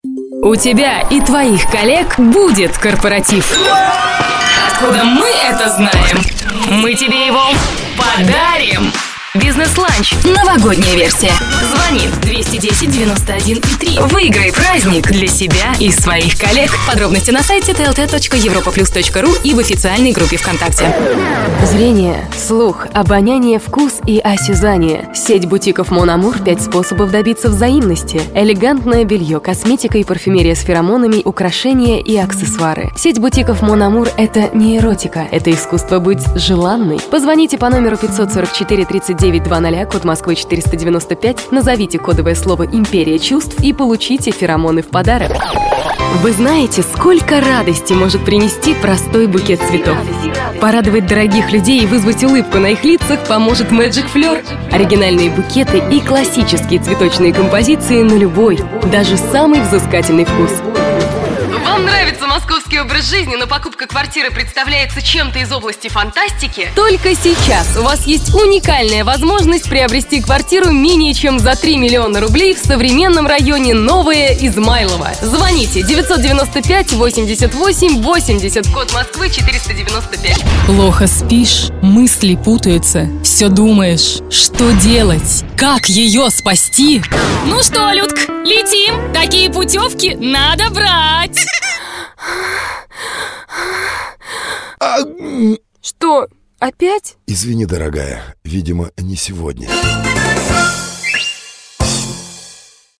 Тракт: микрофон Behringer B2-Pro; карта Focusrite Saffire 6 usb, профессиональная шумоизоляция студии
Демо-запись №1 Скачать